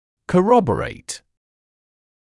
[kə’rɔbəreɪt][кэ’робэрэйт]подтверждать; подкреплять, поддерживать (вывод, утверждение, теорию)